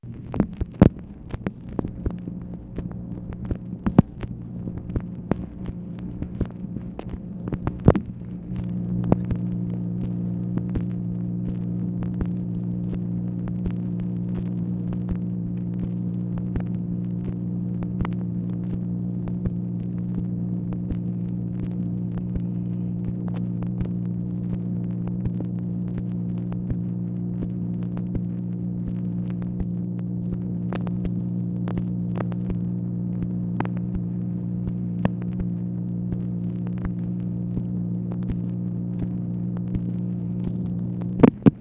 MACHINE NOISE, February 11, 1966
Secret White House Tapes | Lyndon B. Johnson Presidency